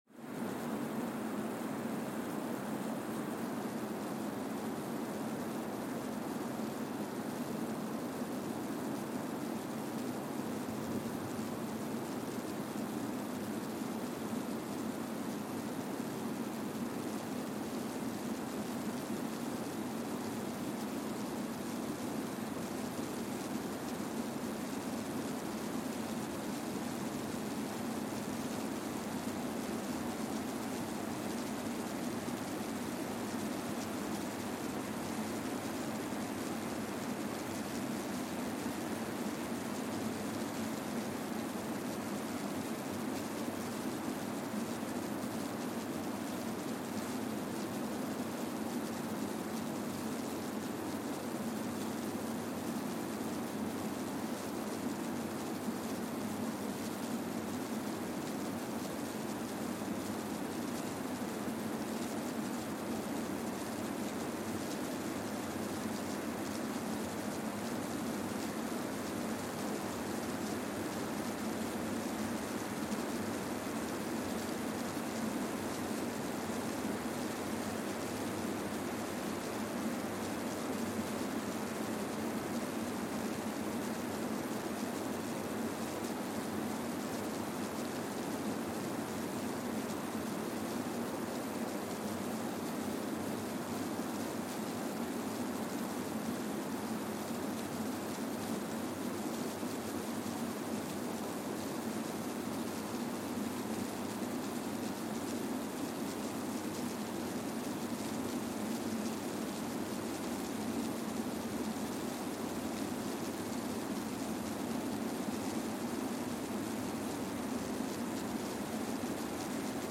Kwajalein Atoll, Marshall Islands (seismic) archived on July 27, 2023
Sensor : Streckeisen STS-5A Seismometer
Speedup : ×1,000 (transposed up about 10 octaves)
Loop duration (audio) : 05:45 (stereo)